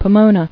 [Po·mo·na]